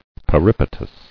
[pe·rip·a·tus]